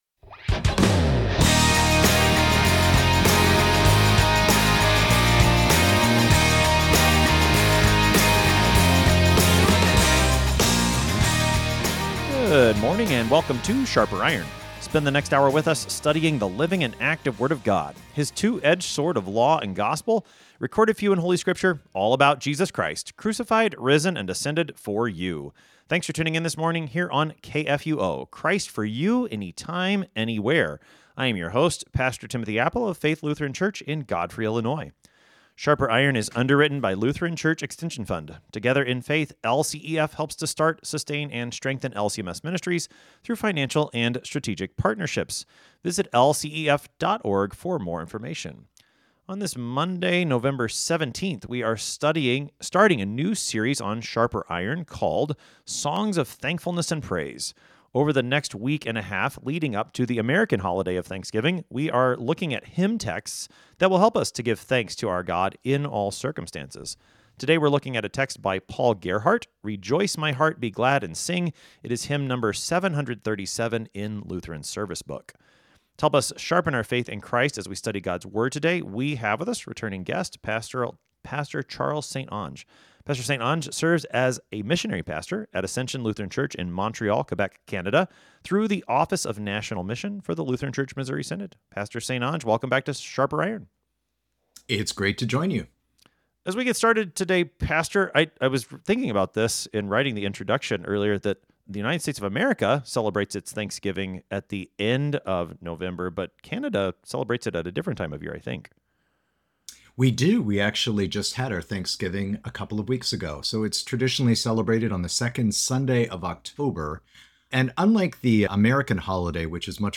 Two pastors engage with God’s Word to sharpen not only their own faith and knowledge, but the faith and knowledge of all who listen.